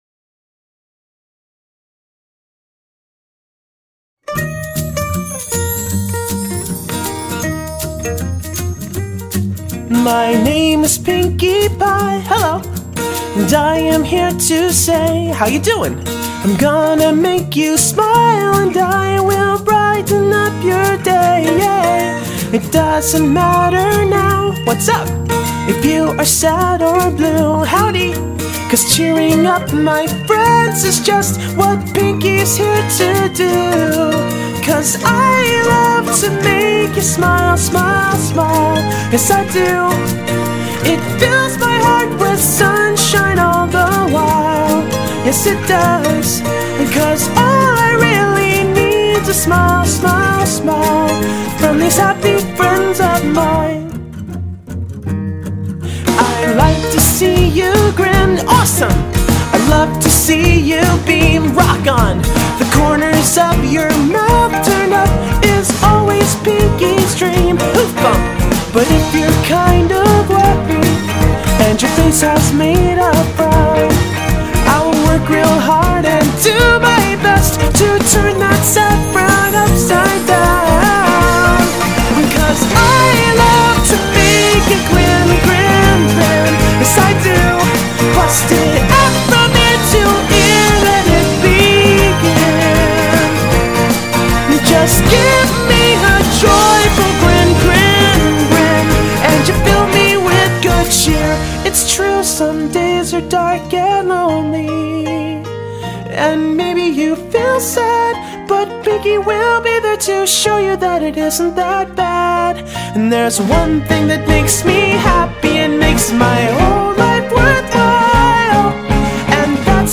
Vocals, guitars, mandolin
Drums, piano, orchestra, synths, bass